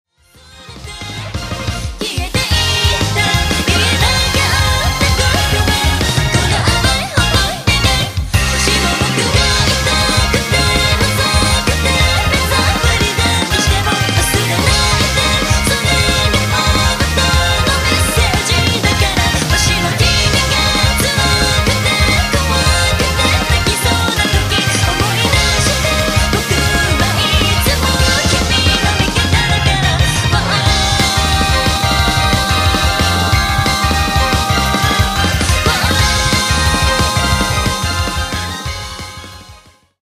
ジャケットイラスト(の一部) 今回はフルボーカルのミニアルバムでございます。